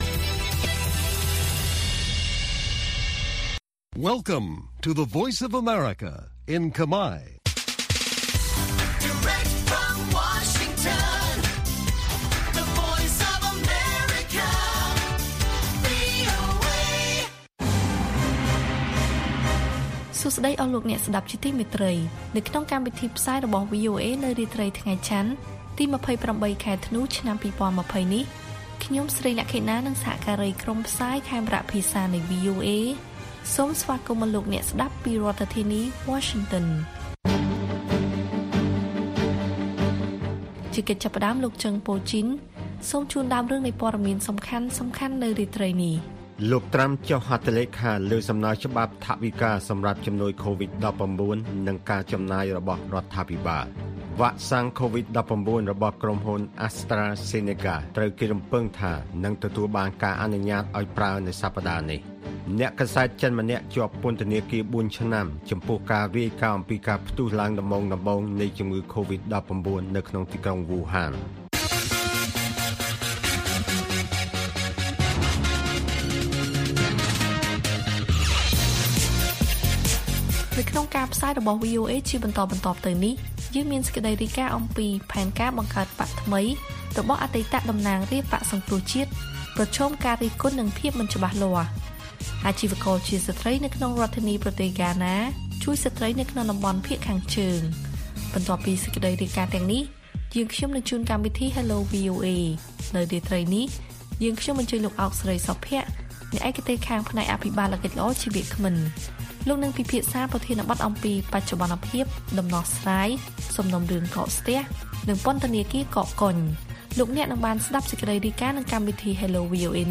ព័ត៌មានពេលរាត្រី៖ ២៨ ធ្នូ ២០២០